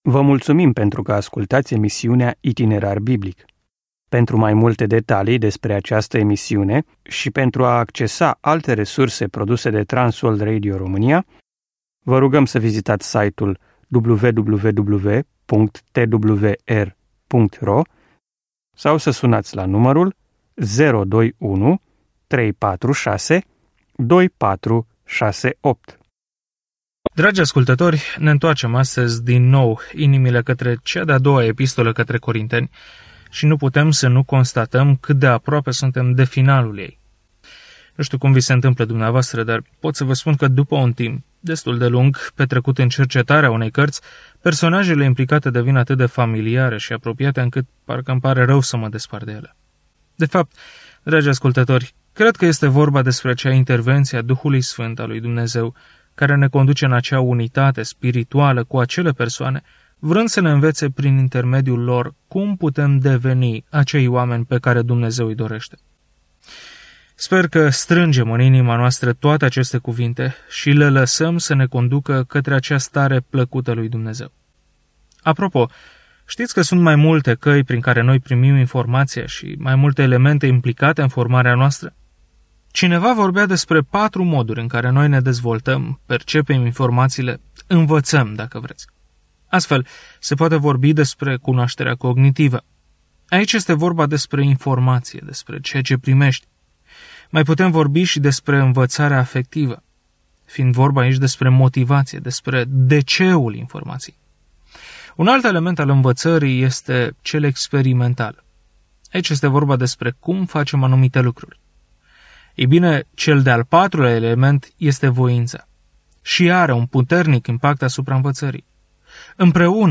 Călătoriți zilnic prin 2 Corinteni în timp ce ascultați studiul audio și citiți versete selectate din Cuvântul lui Dumnezeu.